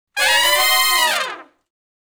012 Fast Climb Up (Ab) har.wav